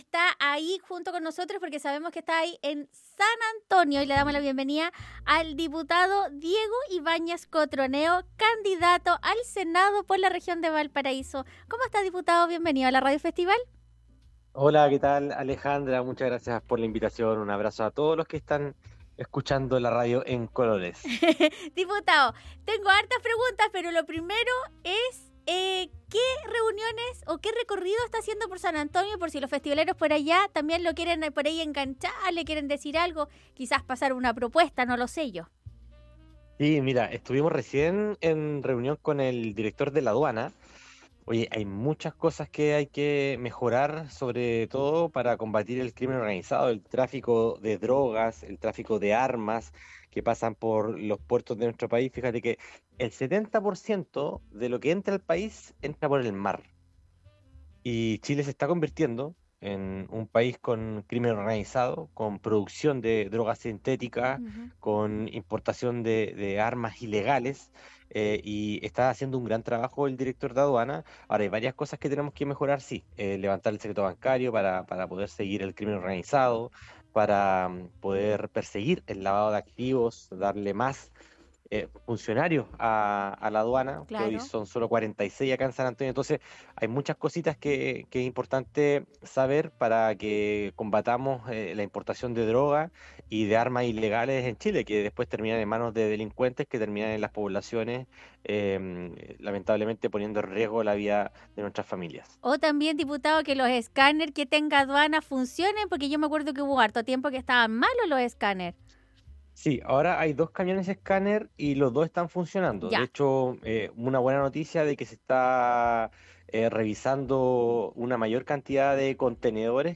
El Diputado del Distrito 6 conversó con radio festival sobre el inicio de la campaña y como espera seguir avanzando en distintos proyectos pero desde el senado.